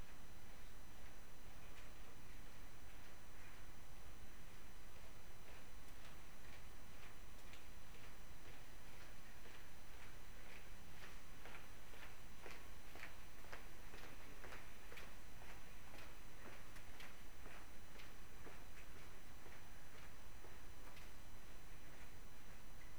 It consists of 43 audio-visual events captured via two cameras (Canon VC-C50i) and two USB microphones in a corridor scenario.
A person walks toward Cam2
Mic 2 audio